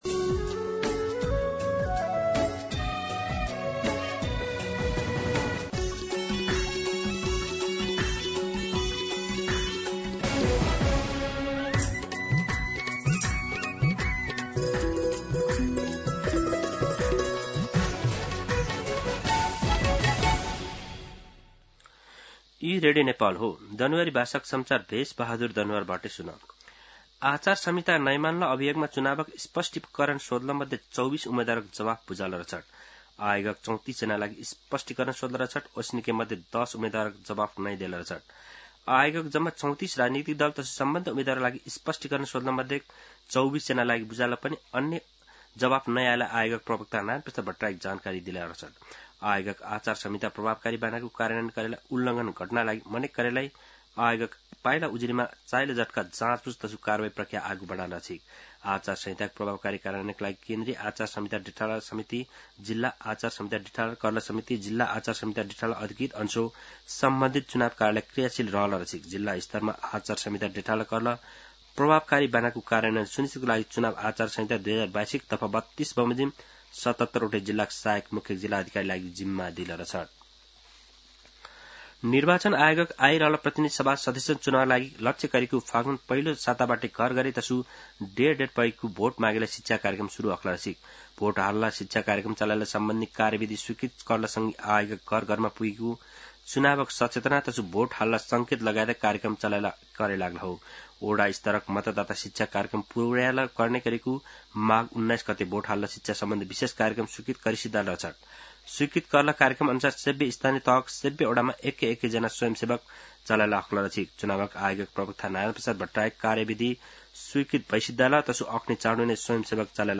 दनुवार भाषामा समाचार : २४ माघ , २०८२
Danuwar-News-10-24.mp3